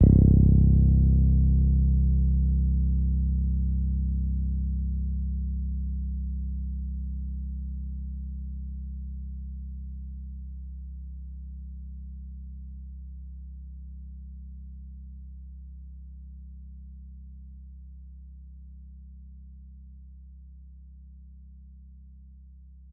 Acoustic Bass.wav